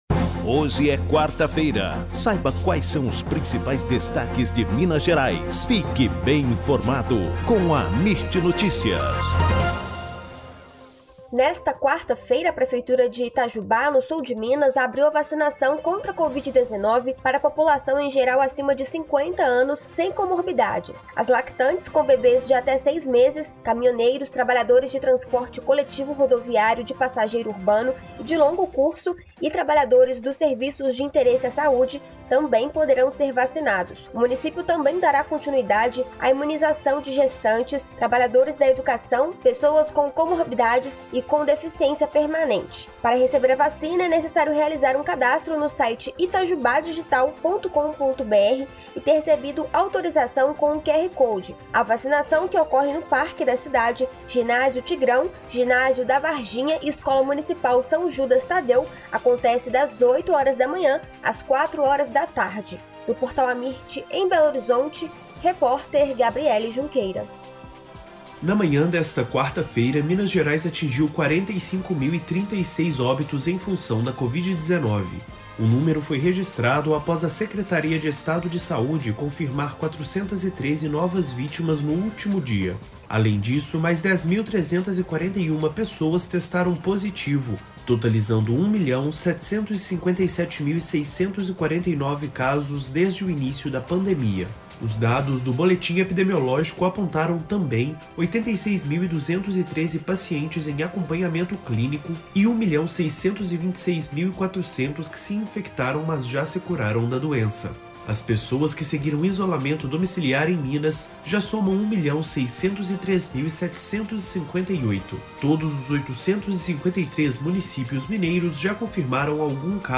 AMIRT Amirt Notícias Destaque Gerais Notícias em áudio Rádio e TelevisãoThe estimated reading time is less than a minute